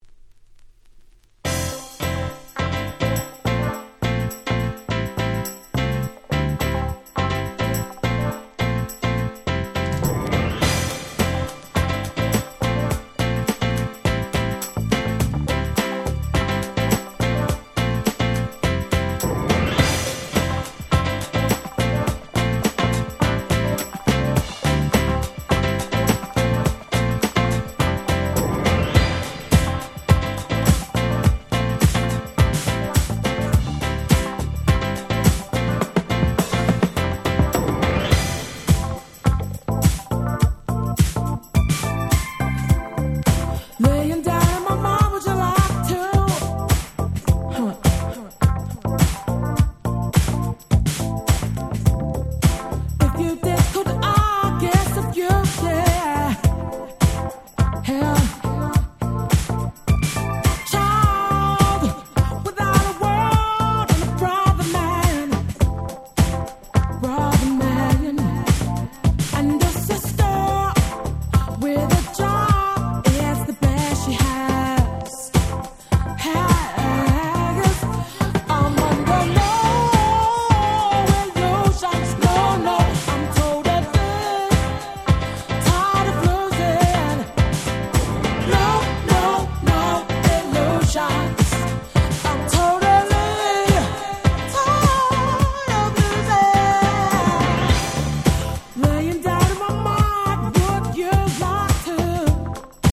Nice UK R&B EP !!
Acid Jazz アシッドジャズ